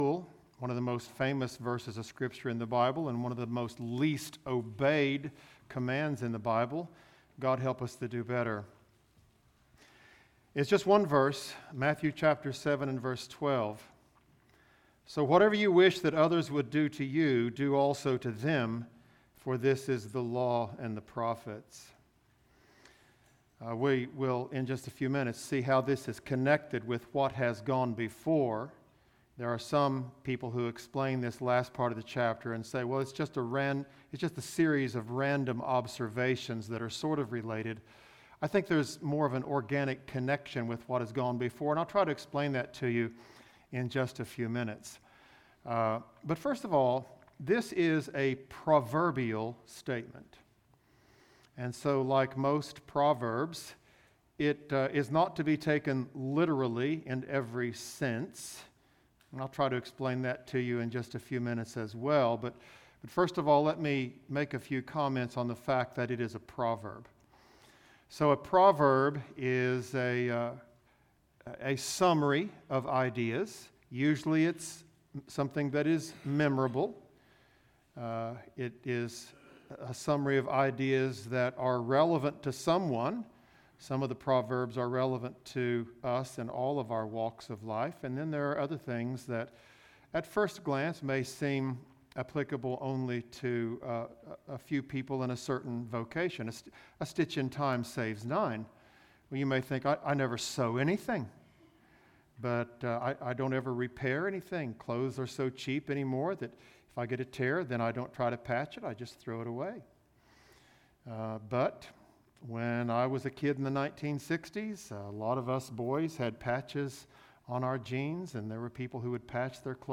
Bullitt Lick Baptist Church - Sermons